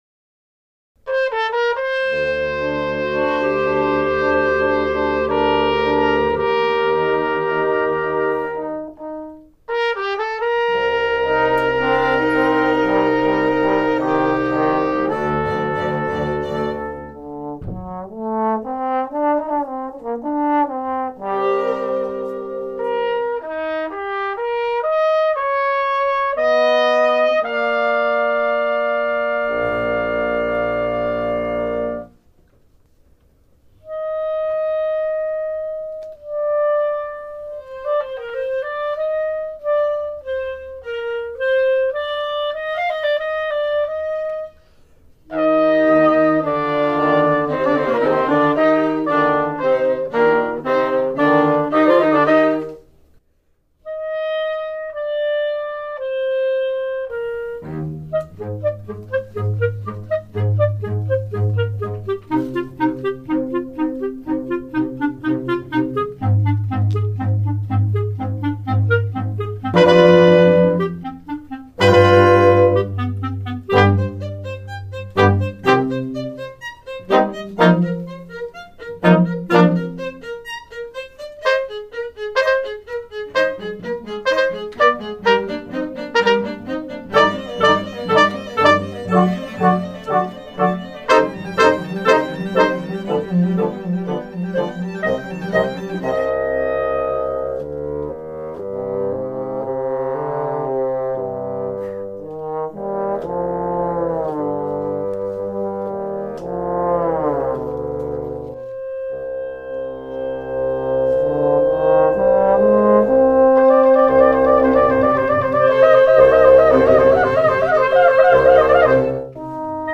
Adagio Allegro Bizarrito Sib 4 Full Score (2007) Juilliard Spring 2007.  Bizarre concerto grosso sextet.